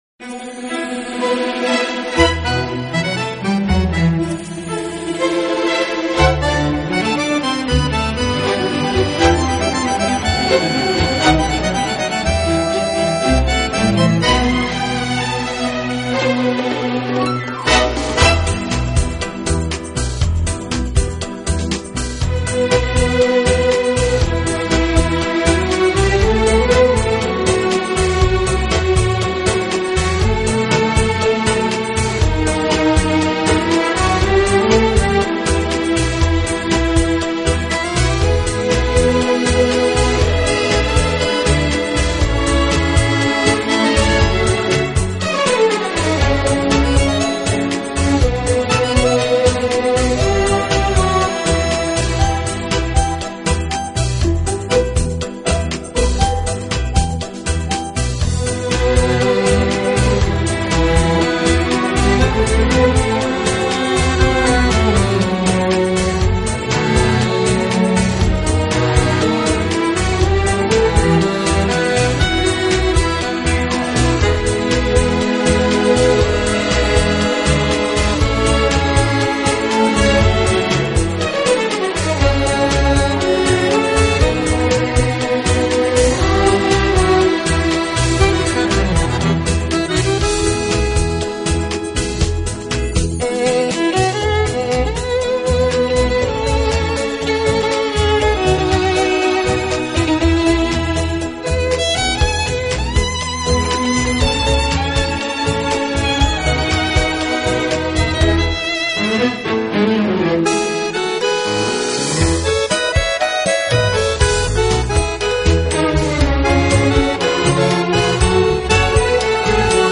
在跳探戈的时候节奏的偶尔停顿让舞者更加兴奋。
新回到活泼的节奏中。